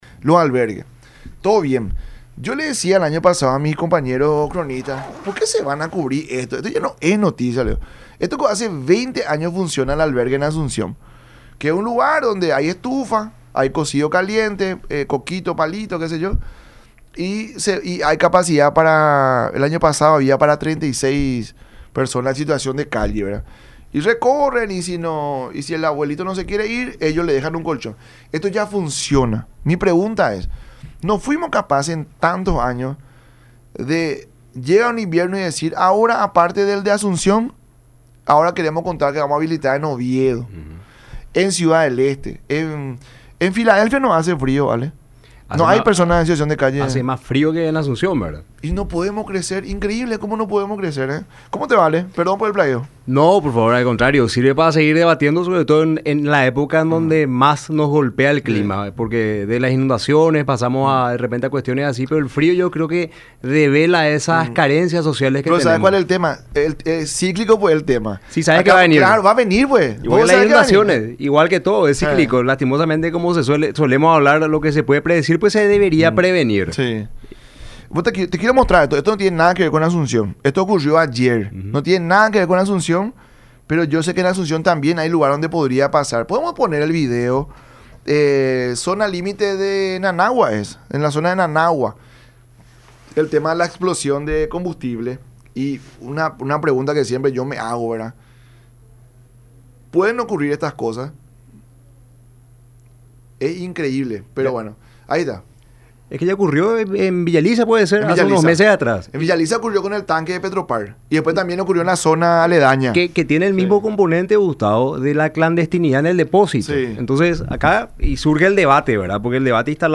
en su visita a los estudios de Unión TV y radio La Unión durante el programa La Mañana De Unión.